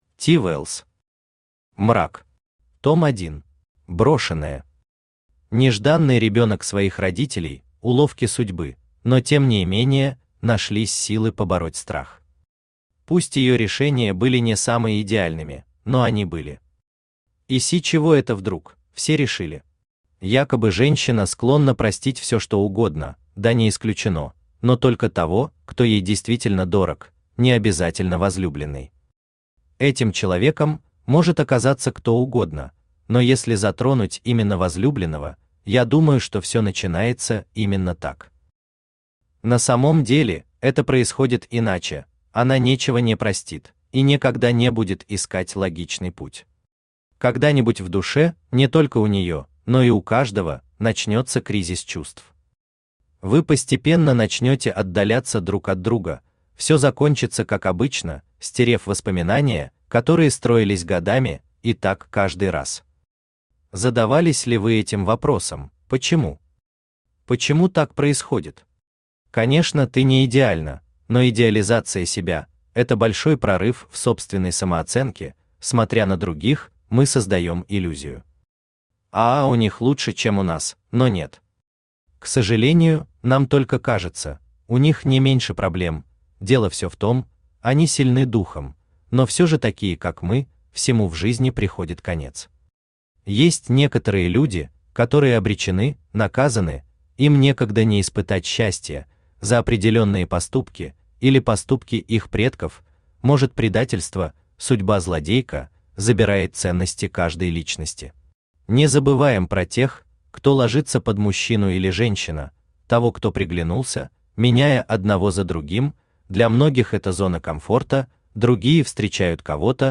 Том 1 Автор T I Wells Читает аудиокнигу Авточтец ЛитРес.